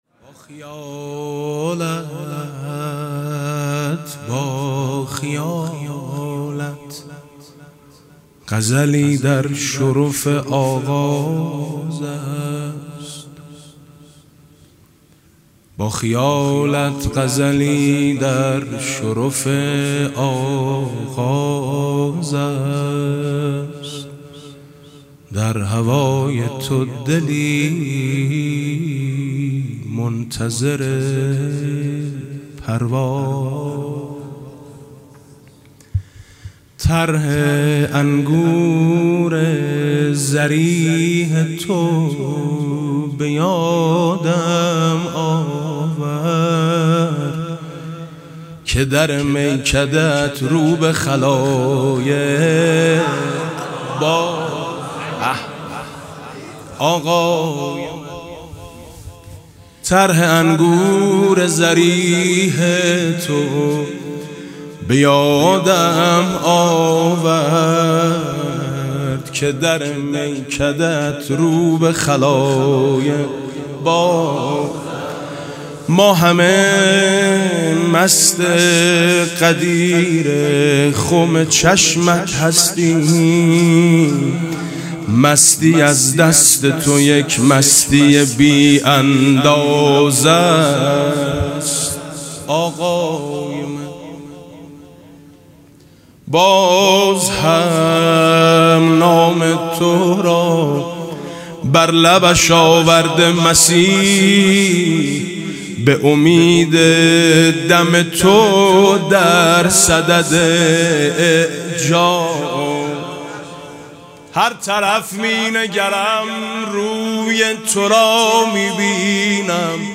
مدح: در هوای تو دلی منتظر پرواز است